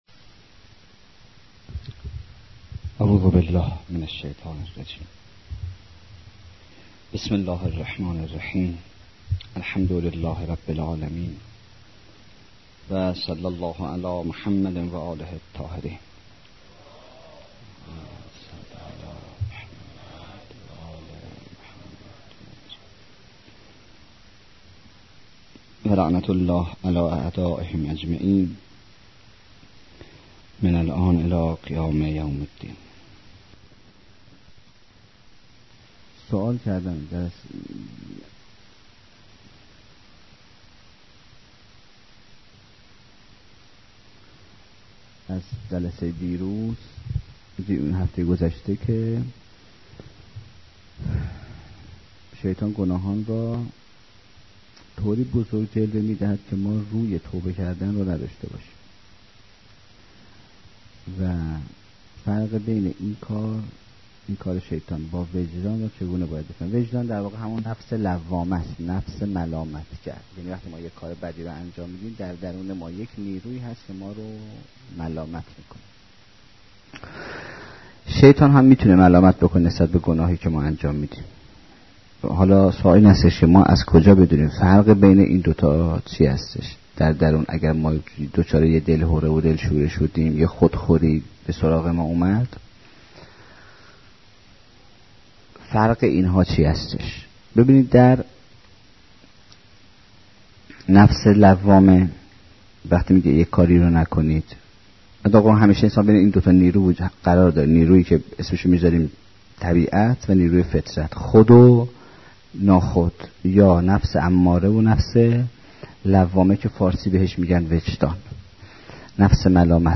دانلود دوازدهمین قسمت از سخنرانی